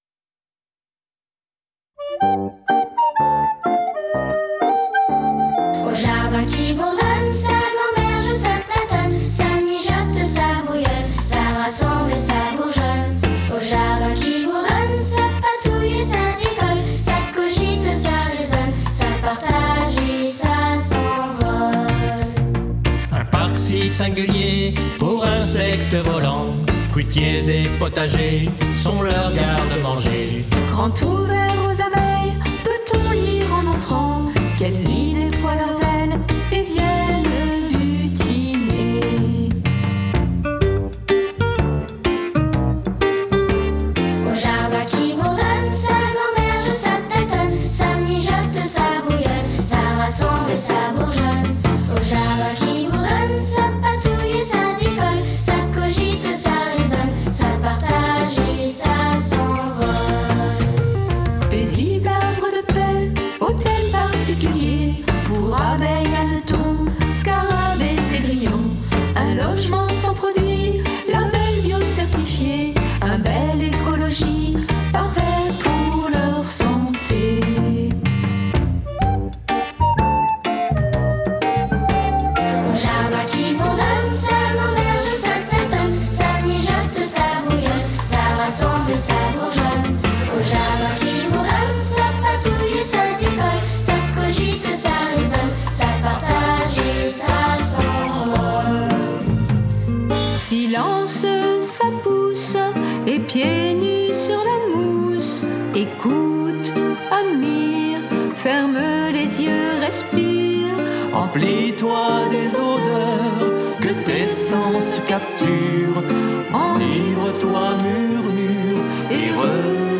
I composed the music and the arrangements.
The chorus was sung by children from Langon schools